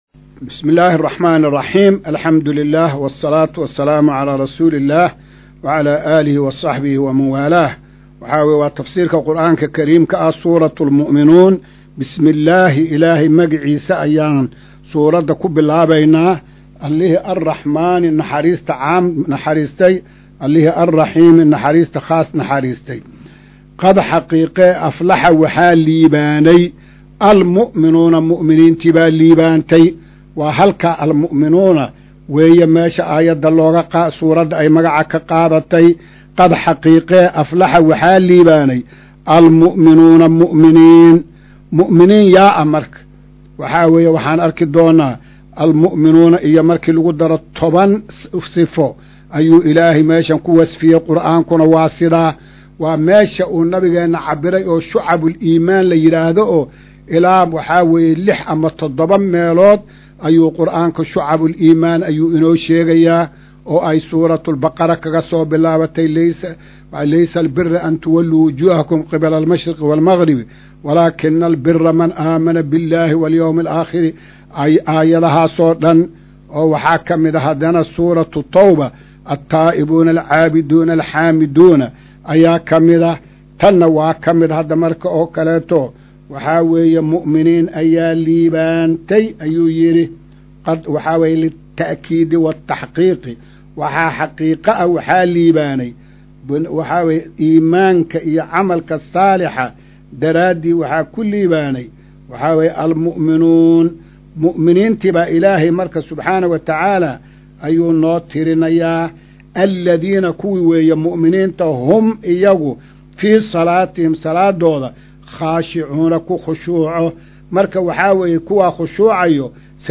Maqal:- Casharka Tafsiirka Qur’aanka Idaacadda Himilo “Darsiga 165aad”